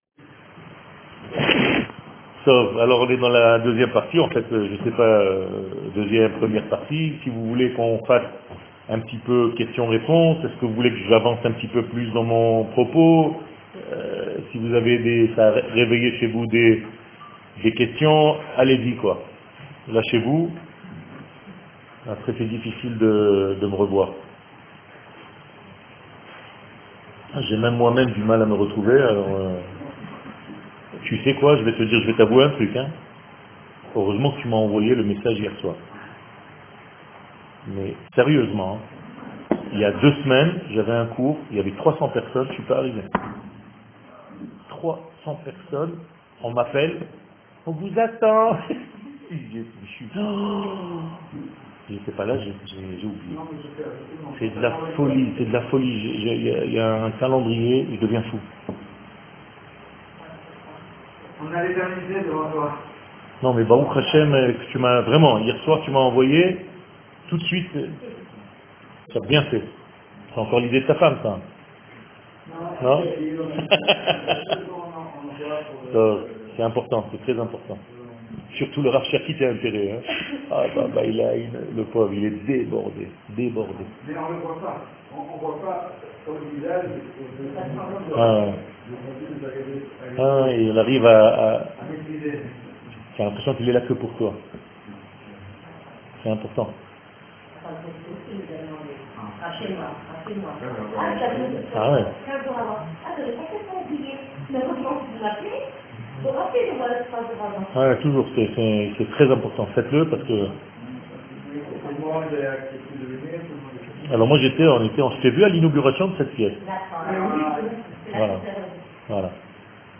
Questions - Reponses - Machon Meir